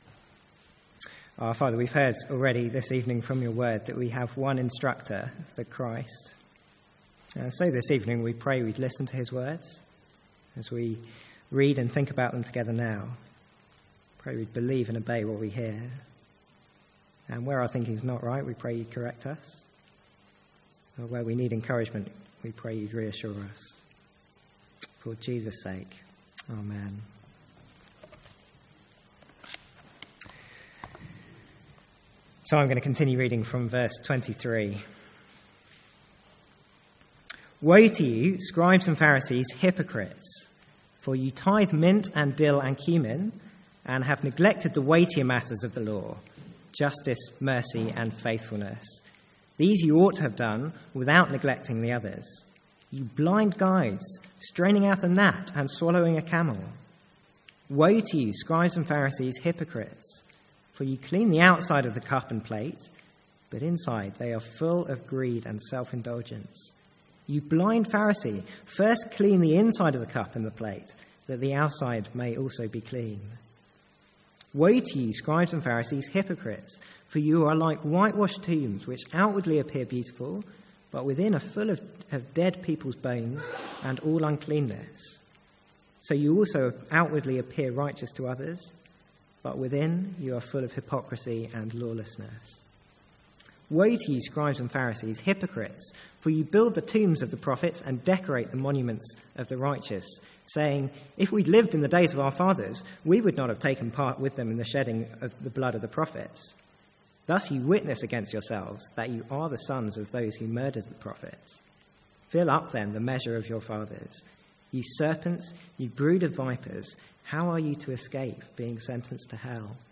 Sermons | St Andrews Free Church
From the Sunday evening series in Matthew.